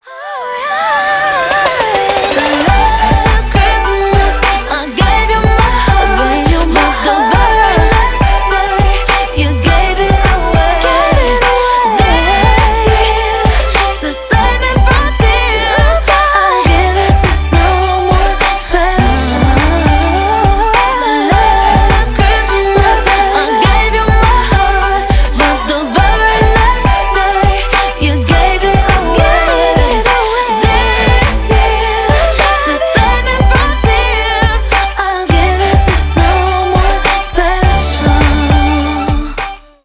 • Качество: 320 kbps, Stereo
Поп Музыка
новогодние